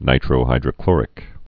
(nītrō-hīdrə-klôrĭk)